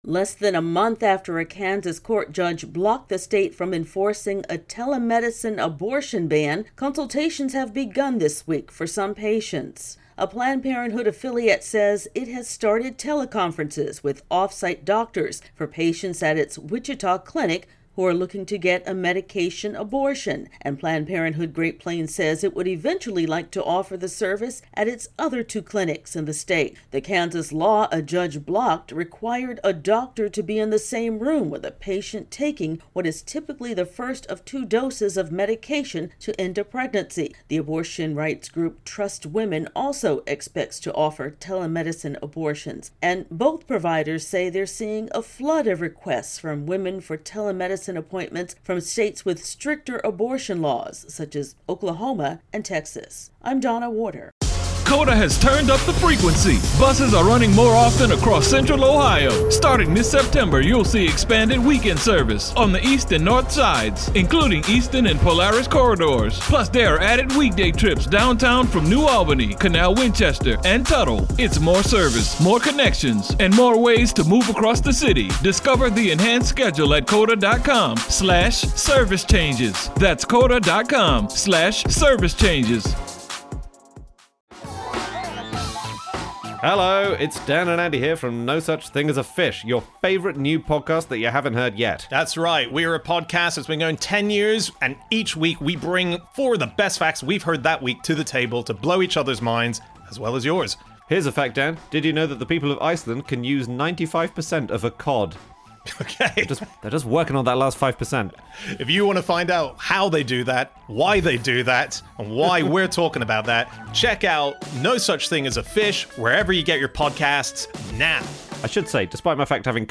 AP correspondent